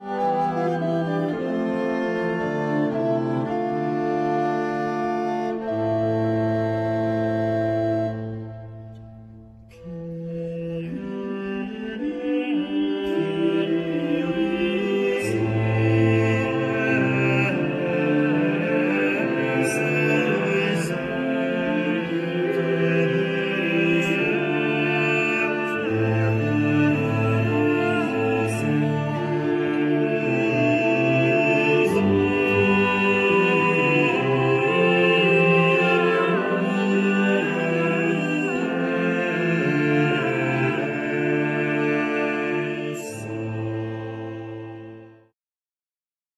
sopran
puzon
kontratenor
tenor
baryton